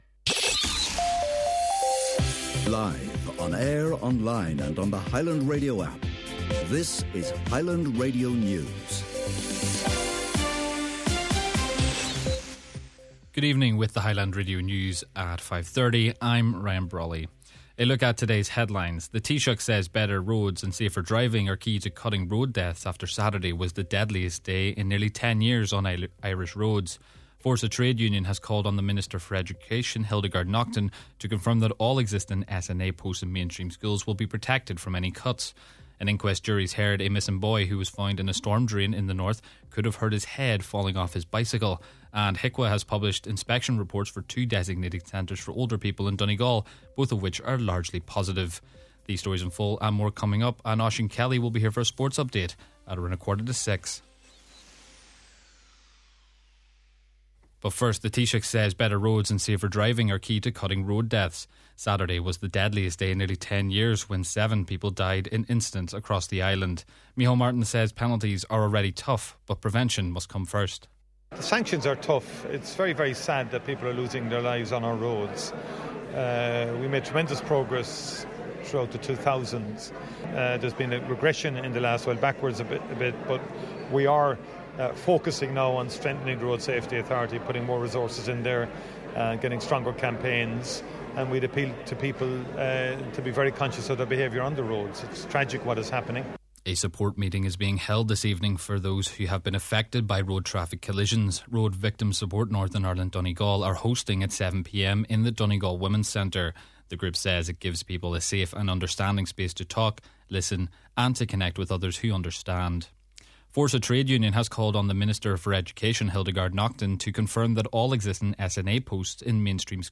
Main Evening News, Sport and Obituary Notices – Monday, February 23rd